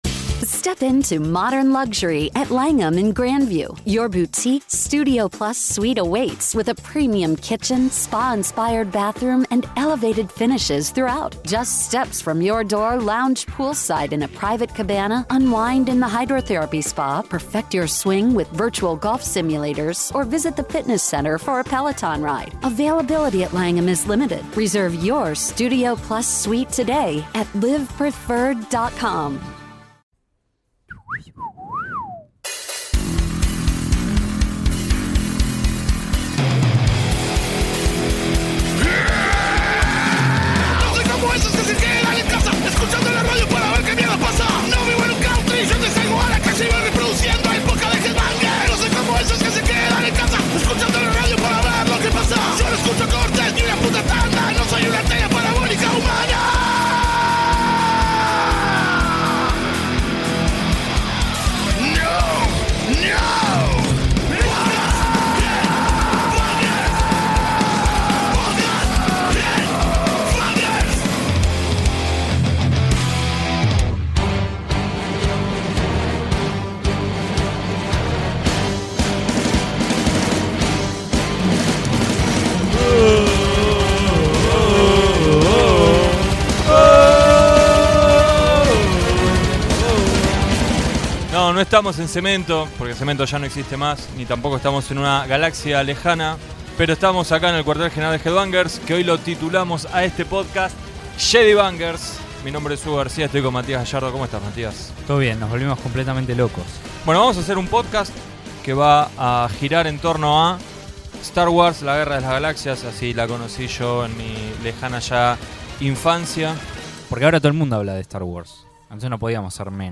Musicalizamos con canciones pesadas relacionadas a Star Wars